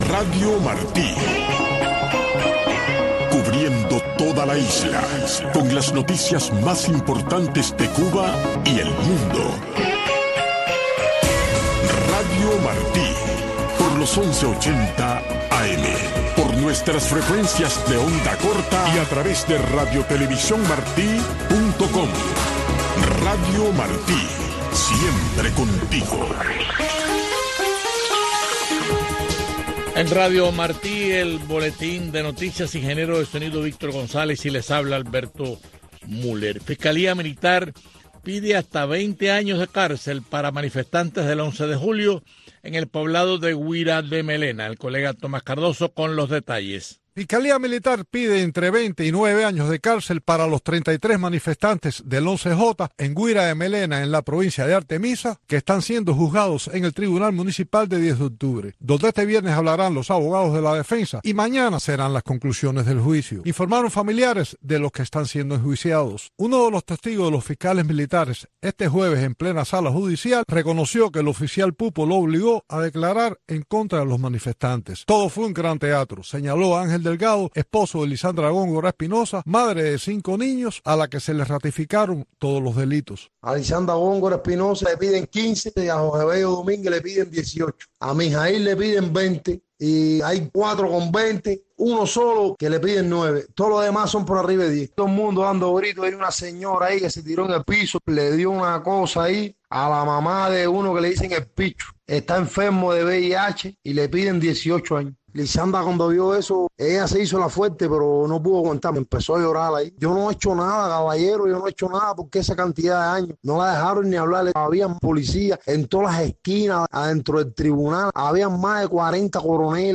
Entrevistas e informaciones con las voces de los protagonistas desde Cuba. Servirá de enlace para el cubano conozca lo que sucede en el país sin censura. La Voz de la oposición